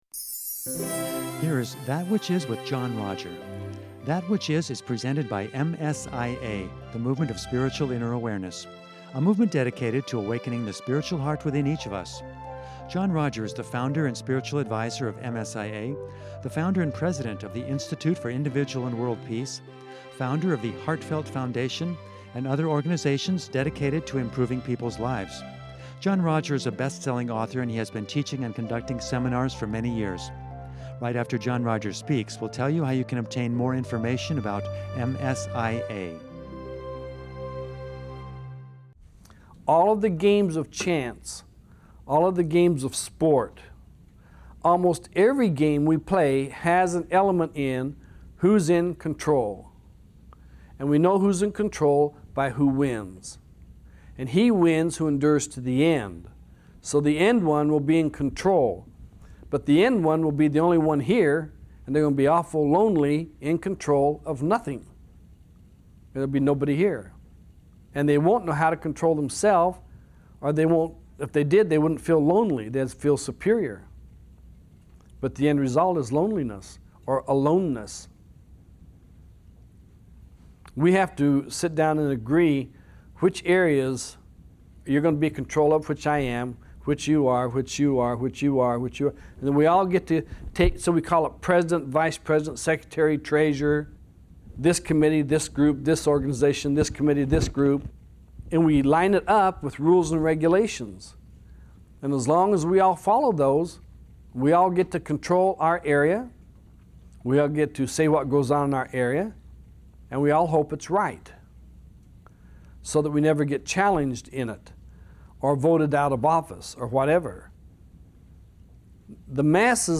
In part two of this seminar